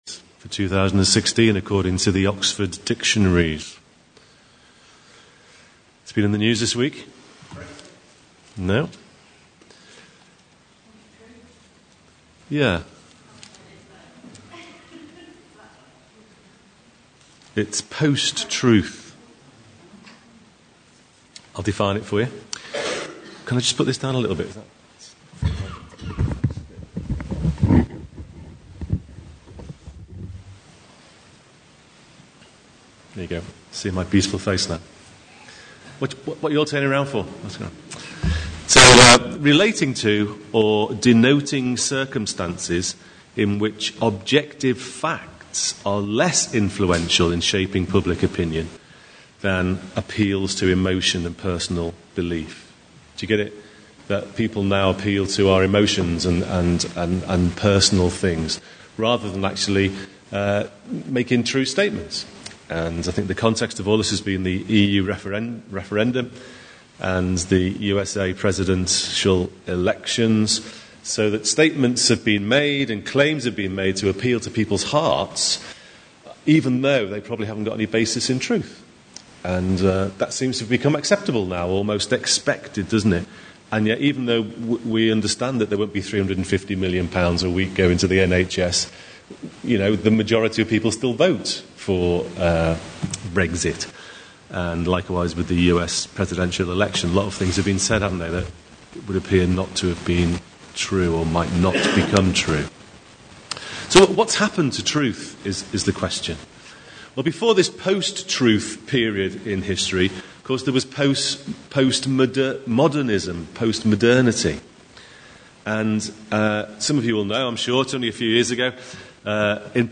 One Mediator (The recording has occasional sound issues)